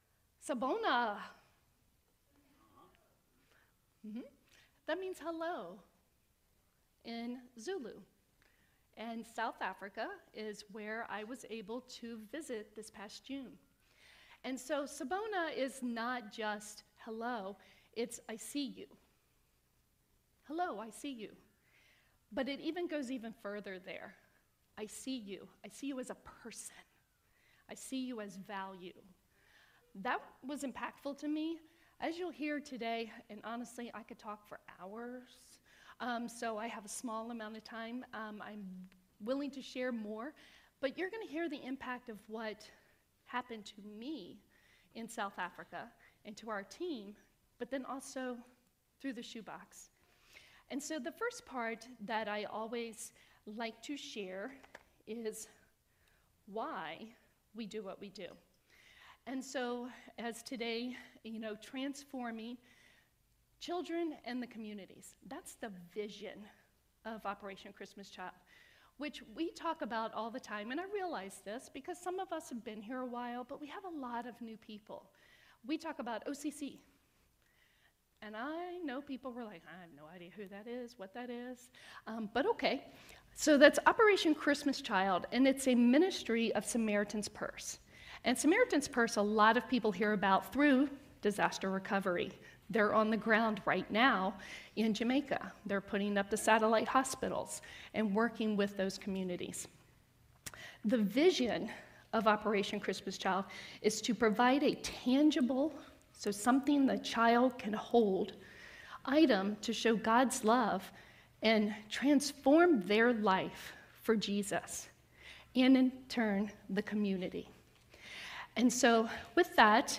Service Type: Sunday Mornings